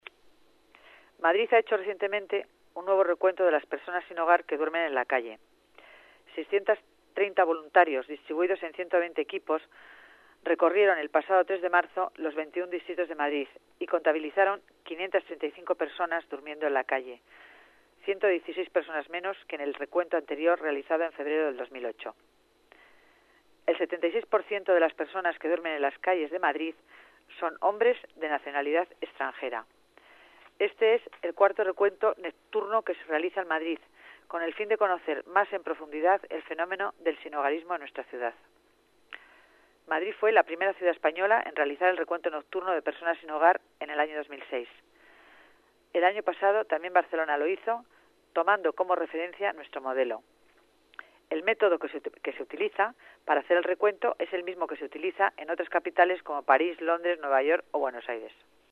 Nueva ventana:Declaraciones delegada Familia y Asuntos Sociales, Concepción Dancausa: recuento personas sin hogar